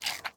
mob / panda / eat7.ogg
eat7.ogg